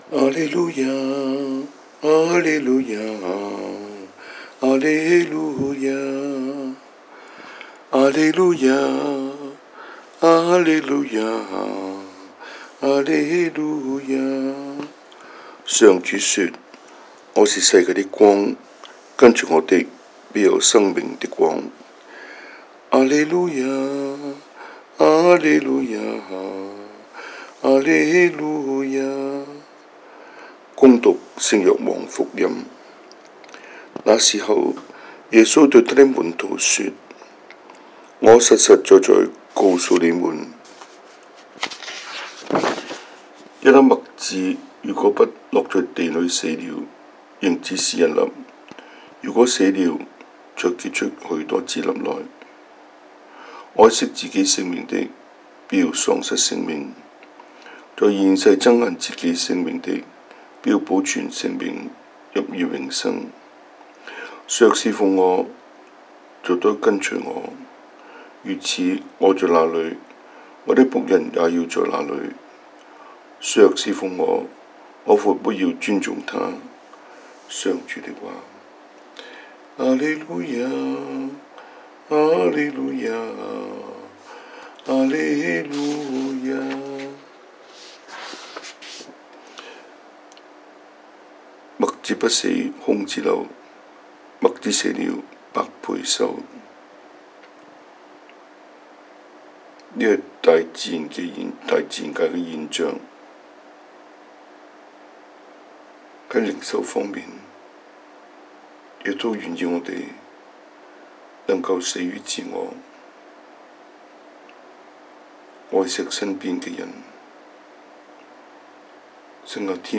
Cantonese Homily, Eng Homily